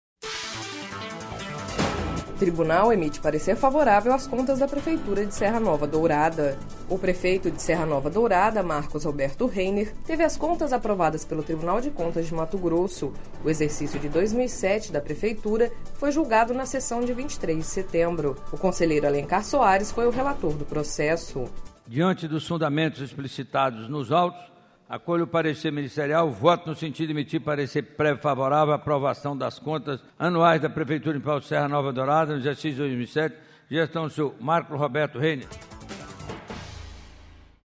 O prefeito de Serra Nova Dourada, Marcos Roberto Reiner, teve às contas aprovadas pelo Tribunal de Contas de Mato Grosso./ O exercício de 2007 da prefeitura foi julgado na sessão de 23 de setembro./ O conselheiro Alencar Soares foi o relator do processo.// Sonora: Alencar Soares – conselheiro do TCE-MT